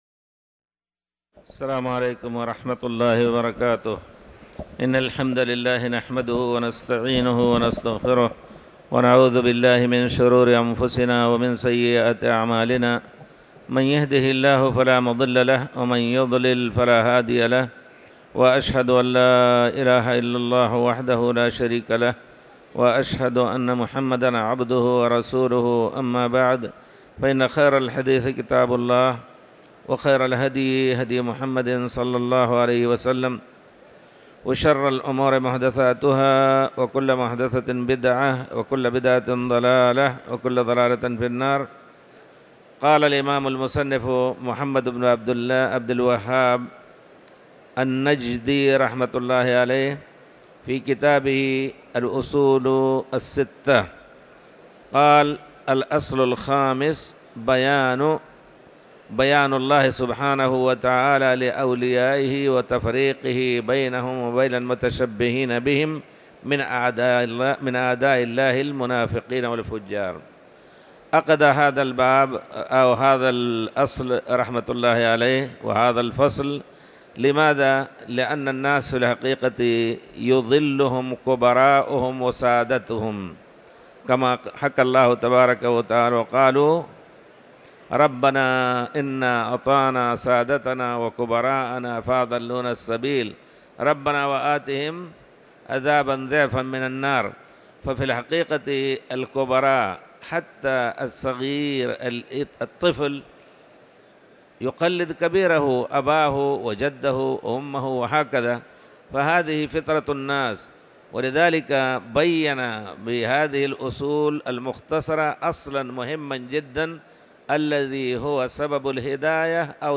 تاريخ النشر ٢٥ رمضان ١٤٤٠ هـ المكان: المسجد الحرام الشيخ